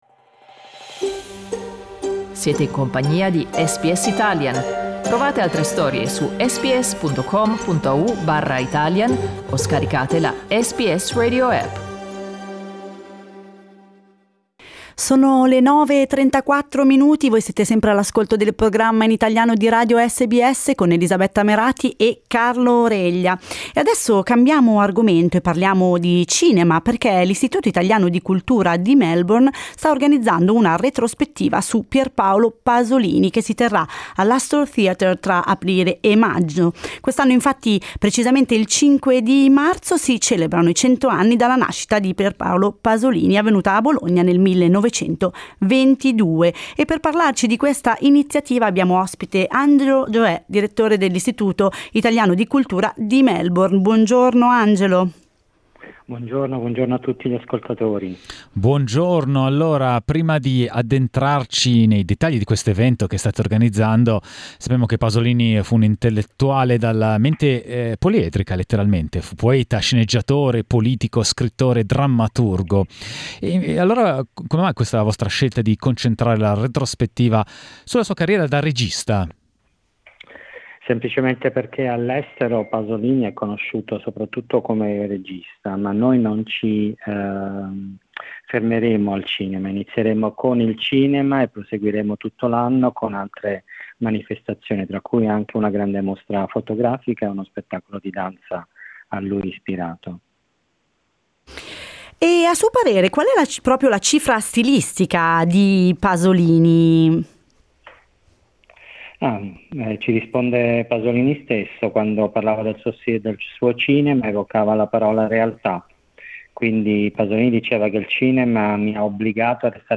Ascolta l'intervento integrale